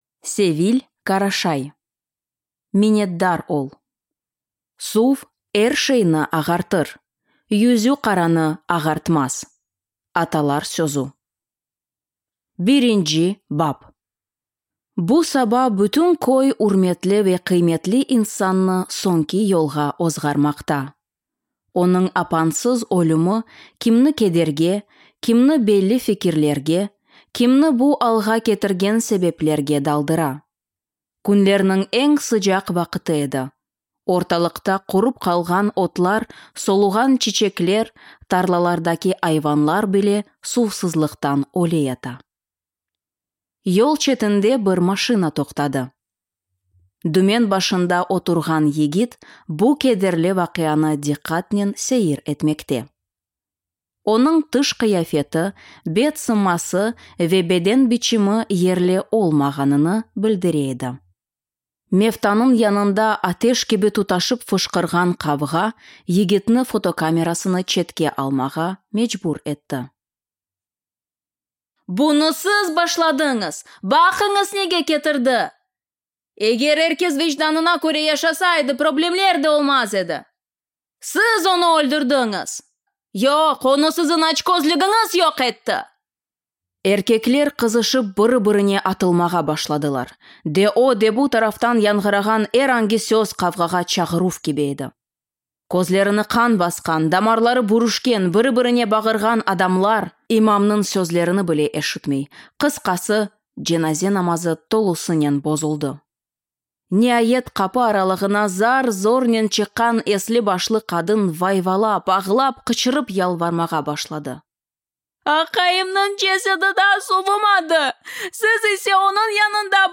Аудиокнига Миннетдар ол!